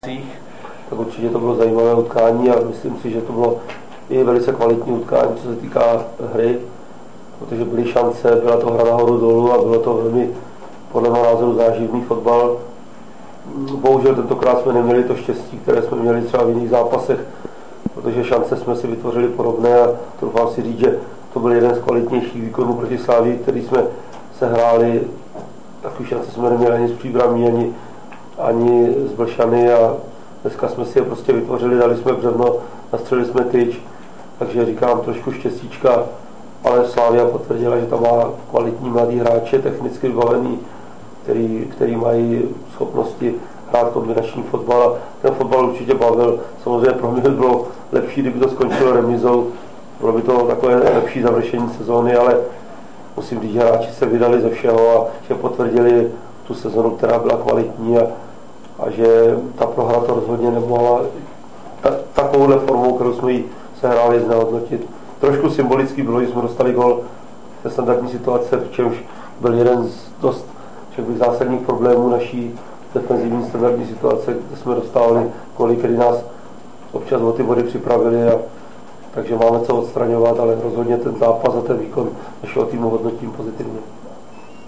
Hodnocení zápasu (MP3):